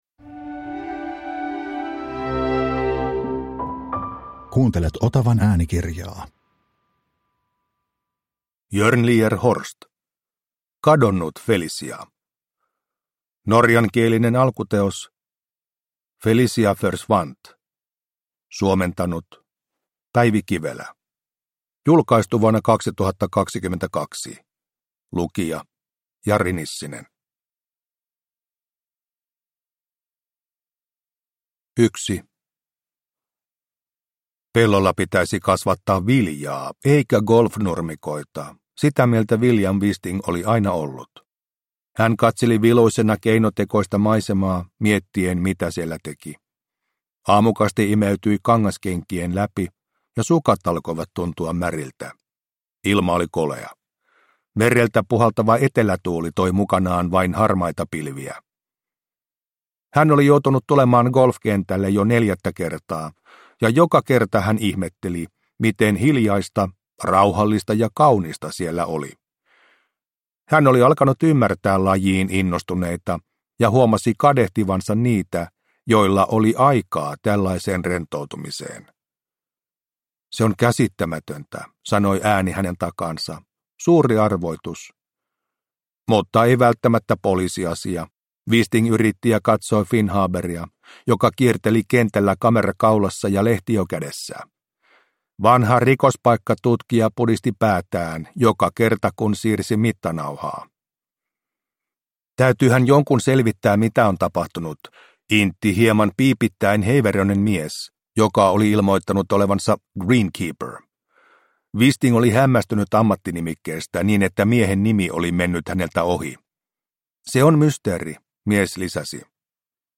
Kadonnut Felicia – Ljudbok – Laddas ner